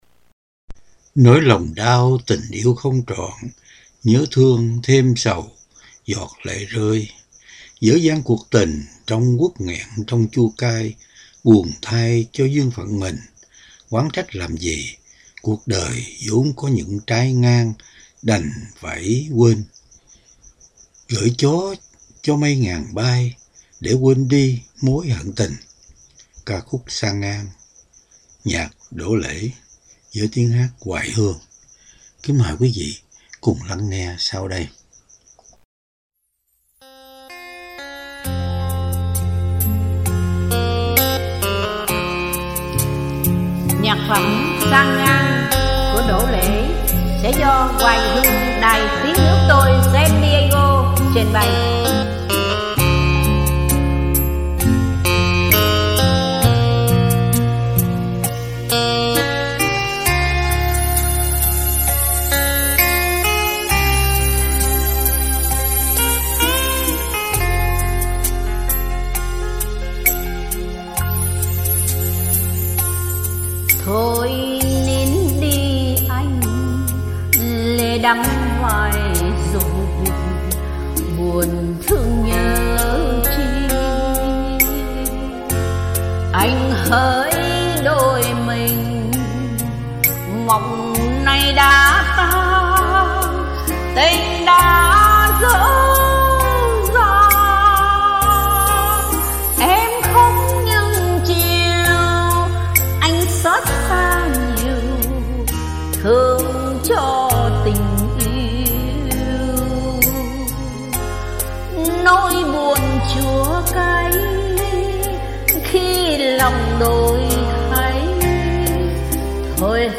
Âm Nhạc